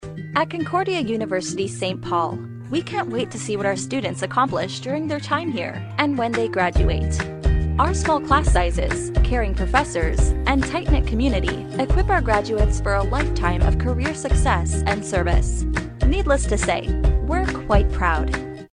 女英104 美式英语 广告 温柔亲切 沉稳|科技感|积极向上|时尚活力|神秘性感|调性走心|亲切甜美
女英104 美式英语 MG动画 沉稳|科技感|积极向上|时尚活力|神秘性感|调性走心|亲切甜美